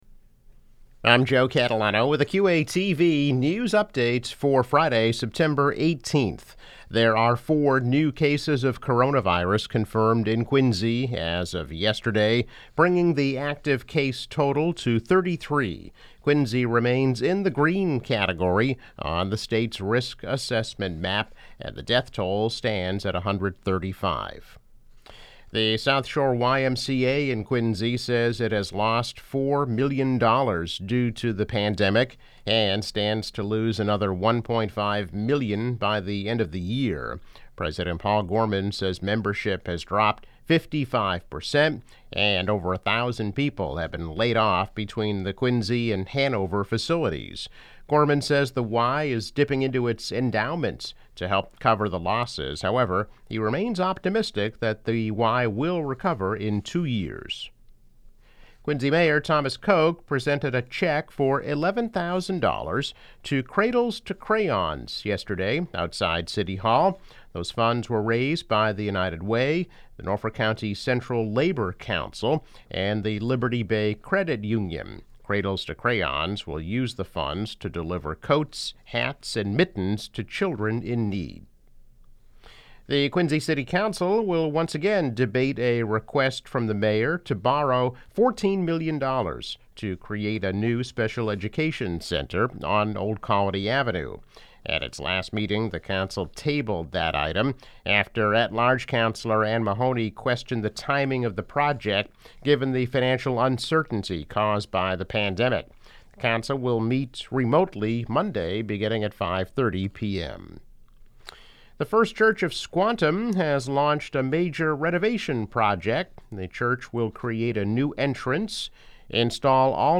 News Update -September 18, 2020